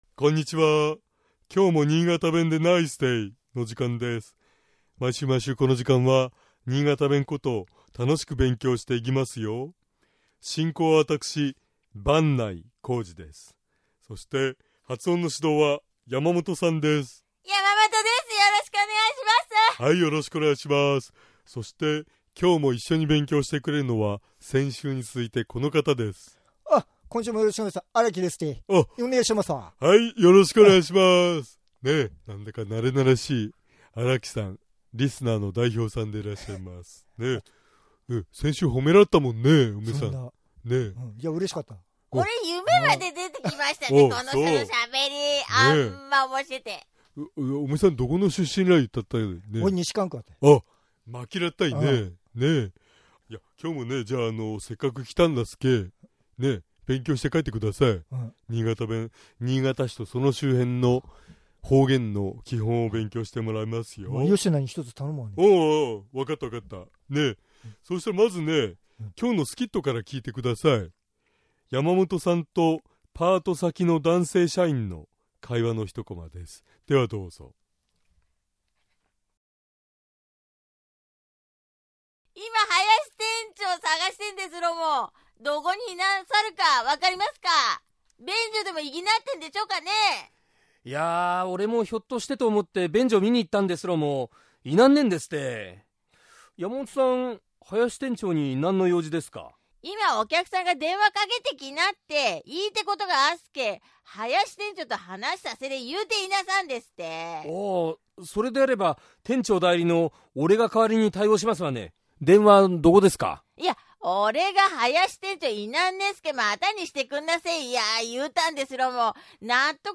尚、このコーナーで紹介している言葉は、 主に新潟市とその周辺で使われている方言ですが、 それでも、世代や地域によって、 使い方、解釈、発音、アクセントなどに 微妙な違いがある事を御了承下さい。